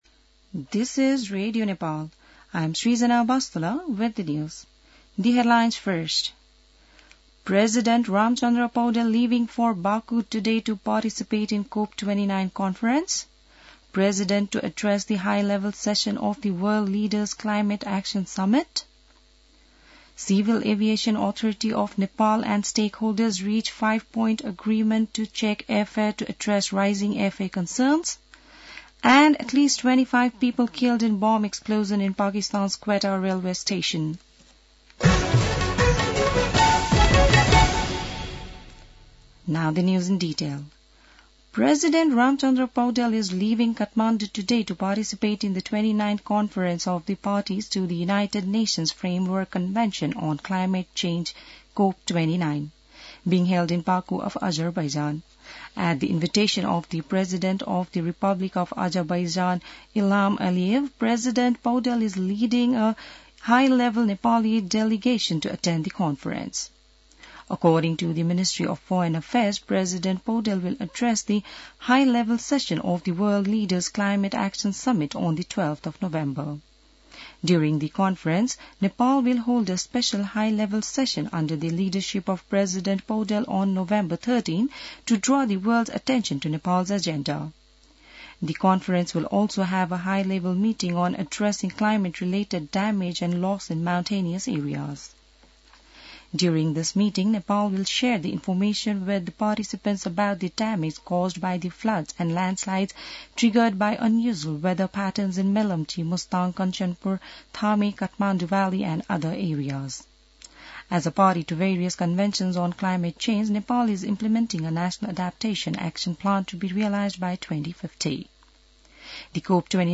बिहान ८ बजेको अङ्ग्रेजी समाचार : २६ कार्तिक , २०८१